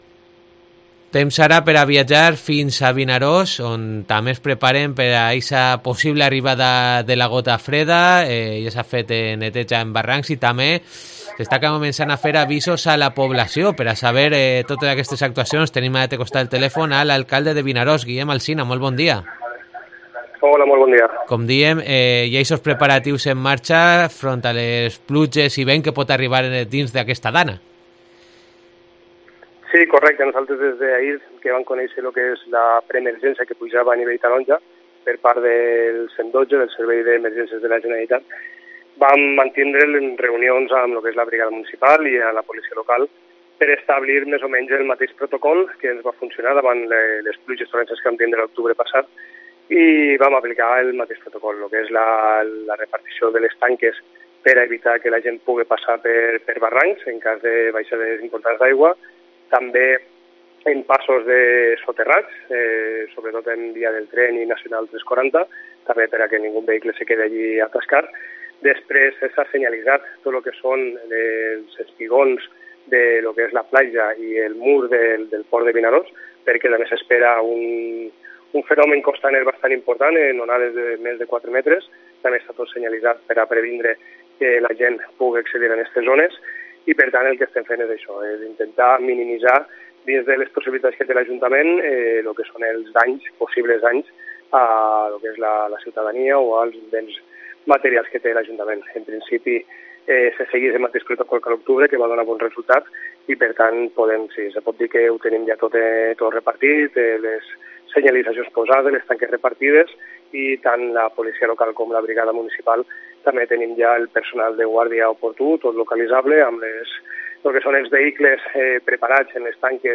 El alcalde de Vinaròs, Guillem Alsina, presenta el dispositivo ante la gota fría